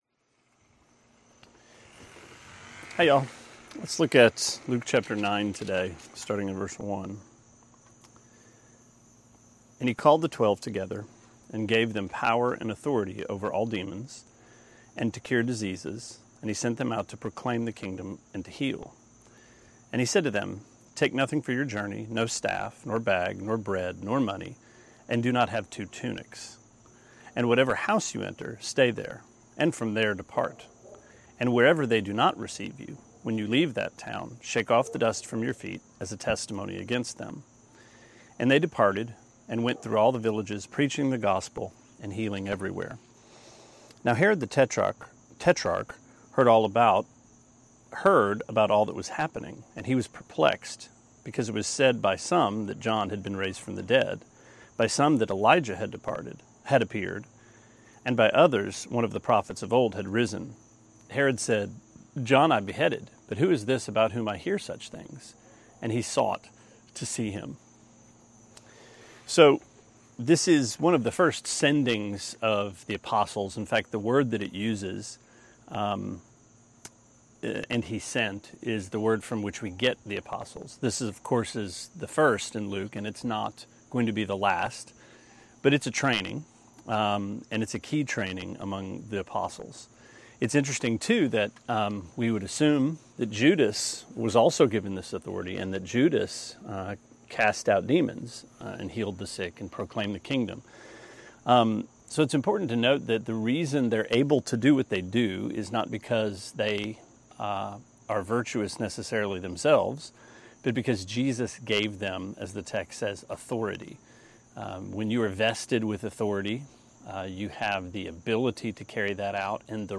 Sermonette 9/25: Luke 9:1-9: Power Over Demons